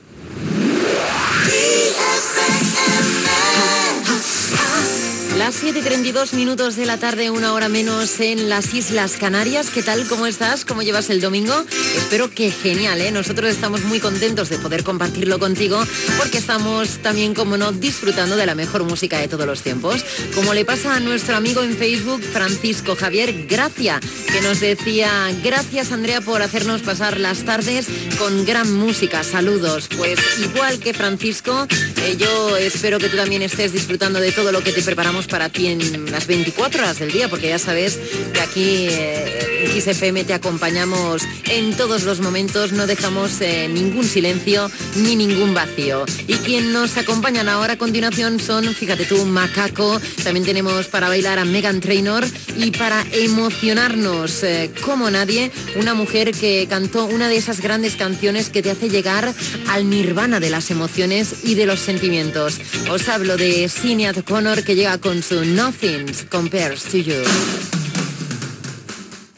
Idicatiu, hora, lectura d'un missatge de Fcebook,propers artistes que sonaran i tema musical
Musical